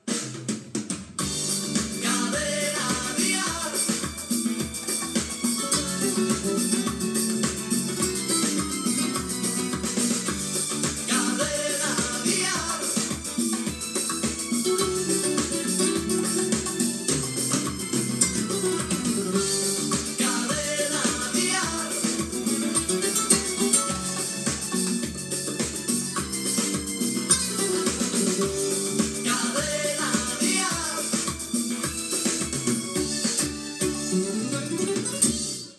Indicatiu de la cadena